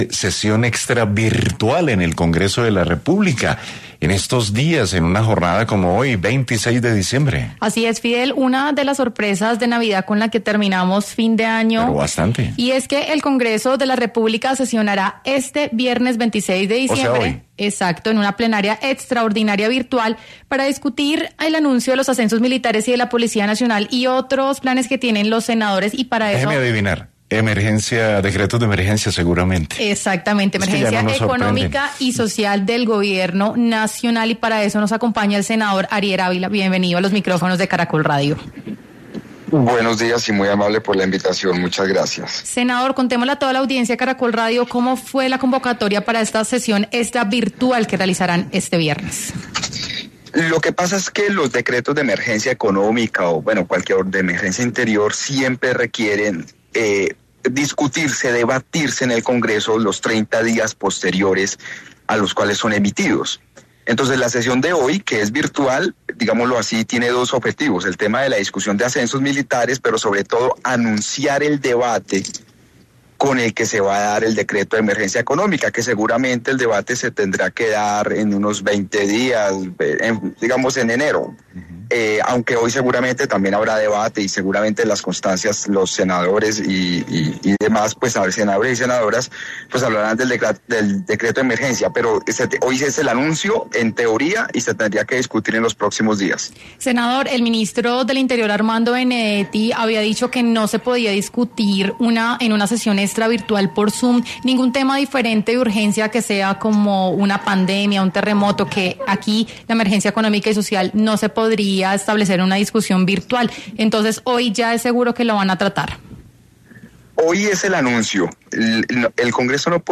Así lo confirmó en entrevista con Caracol Radio, en el marco de una sesión plenaria extraordinaria virtual realizada este 26 de diciembre.